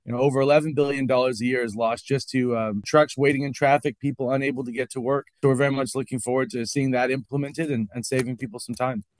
We spoke with Allsopp about his role and hitting the ground running.